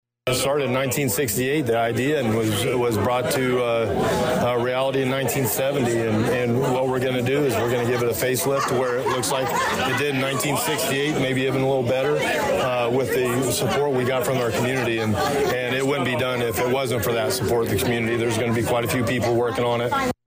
The miniature school is just one of several buildings being renovated or replaced at the Friendly Town Traffic Safety Education Center.  Danville Police Chief Christopher Yates notes Friendly Town has been around for over fifty years….